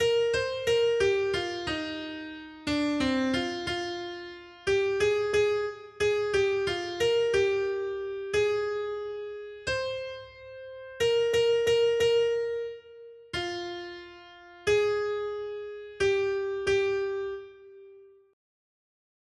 Noty Štítky, zpěvníky ol328.pdf responsoriální žalm Žaltář (Olejník) 328 Skrýt akordy R: Hospodinova láska od věků pro ty, kdo se ho bojí. 1.